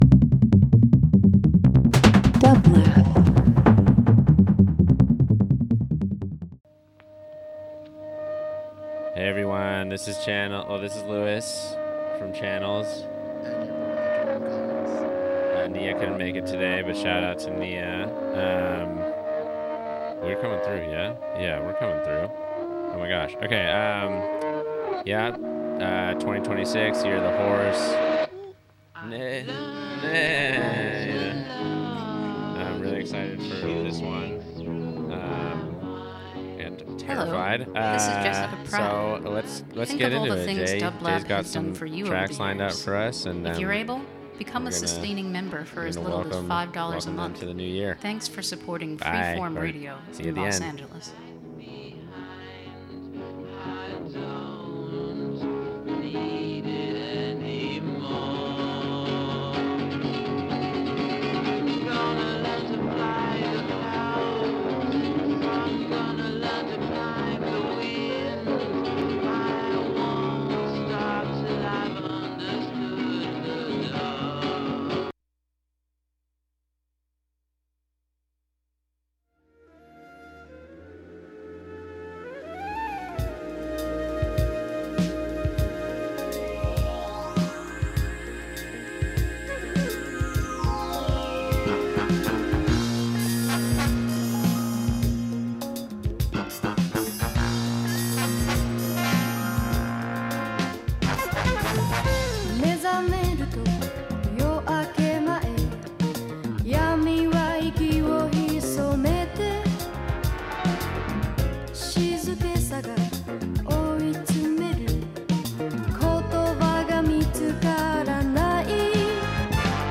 Whether it’s independent radio stations, music venues, underground clubs, or other public art spaces, Channels explores the rich cultural tapestry of the world around us. The music is carefully selected to reflect the thoughts, feelings, and subjects that emerge during each conversation, creating a truly immersive and thought-provoking listening experience.